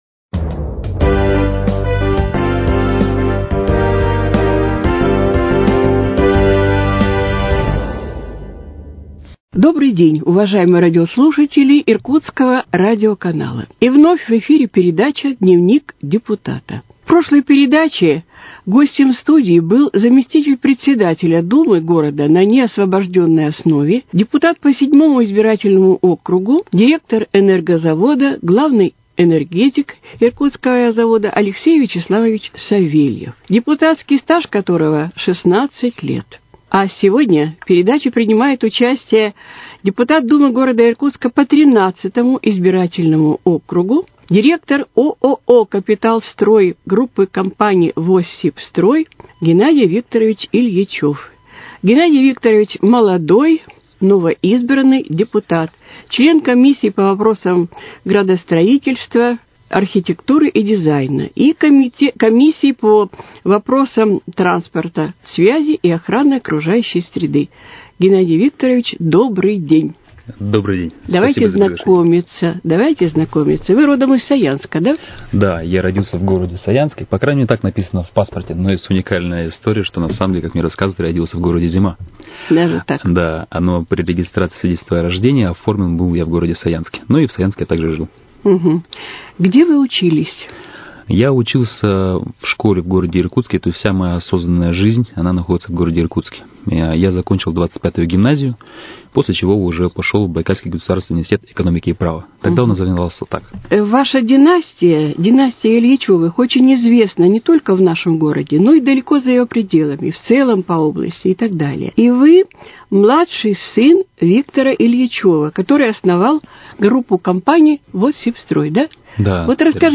беседует с депутатом Думы г.Иркутска по округу № 13 Геннадием Ильичёвым.